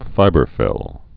(fībər-fĭl)